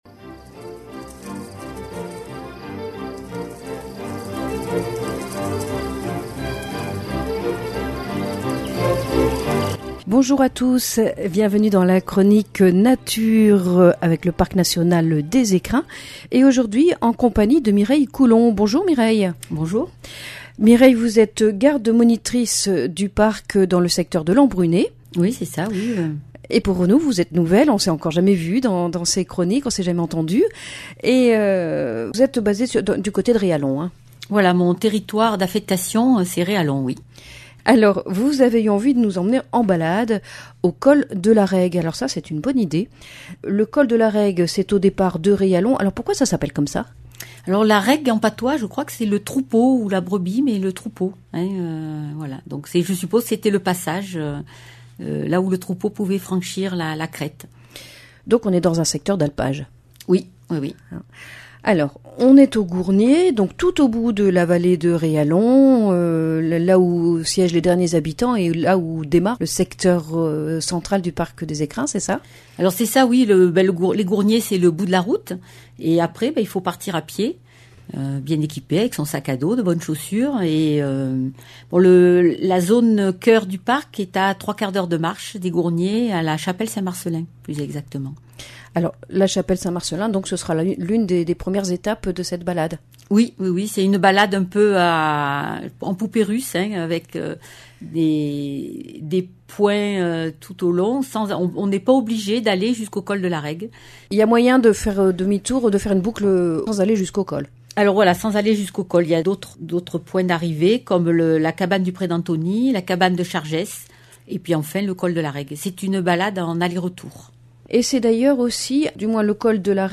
• Chronique nature